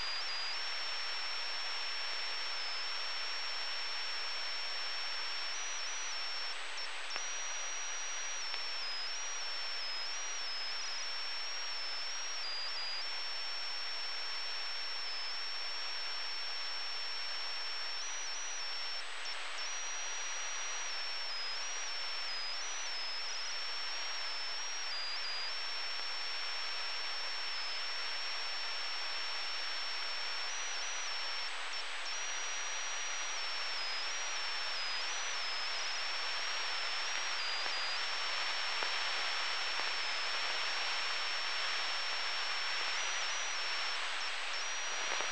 Frequency-  136.654 FM
Receiver-     ICOM IC-R7100
Antenna-     4 element yagi for 2 meter band.  Antenna was fixed, pointing WEST with an elevation of 60 degrees.
While monitoring 5B5, I heard an Airliner slightly off frequency requesting a wheel chair for one of its passengers.